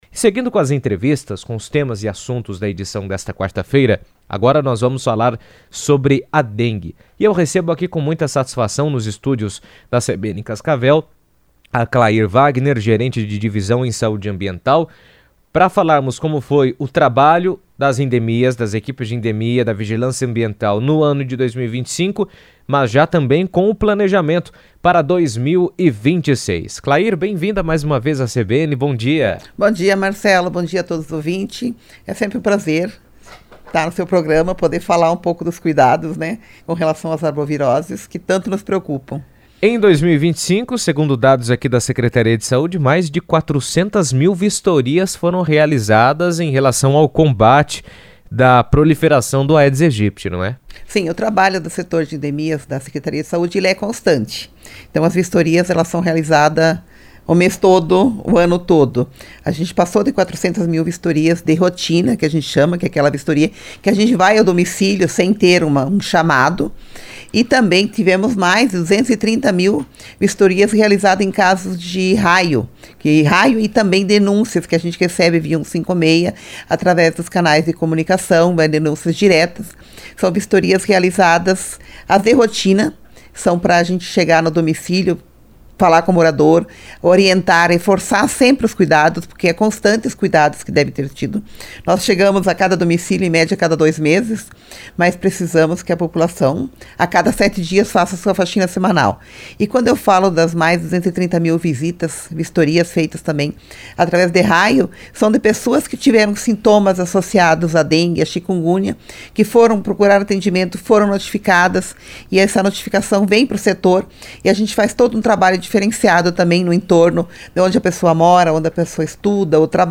comentou sobre o tema em entrevista à rádio CBN